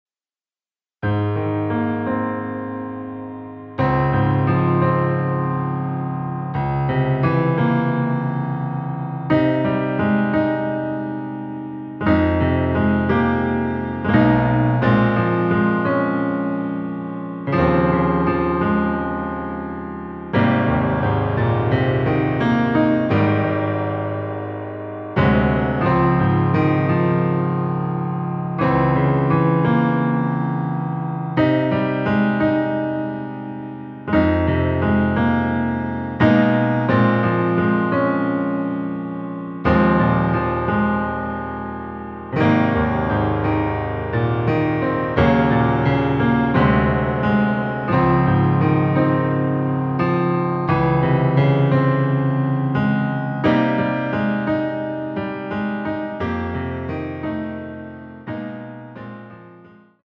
Ab
앞부분30초, 뒷부분30초씩 편집해서 올려 드리고 있습니다.